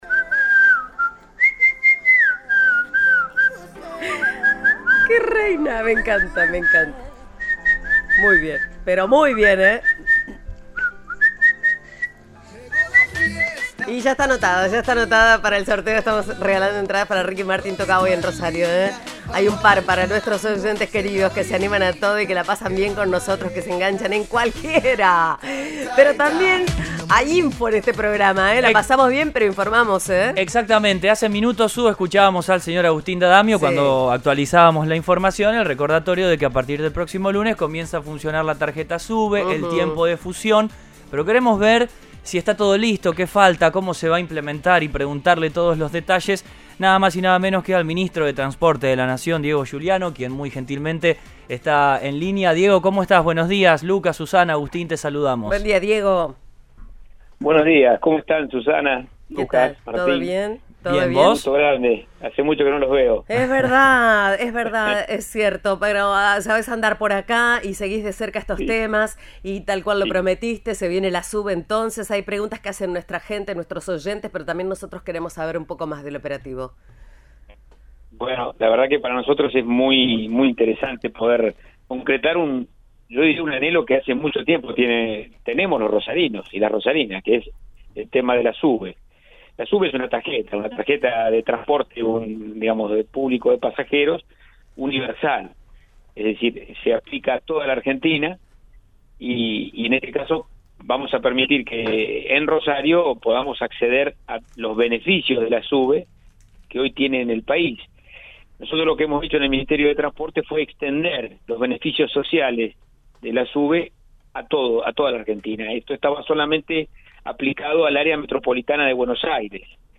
El ministro de Transporte de la Nación habló en Cadena 3 Rosario sobre la implementación del sistema que comenzará a funcionar desde este lunes (junto a la Movi por 120 días).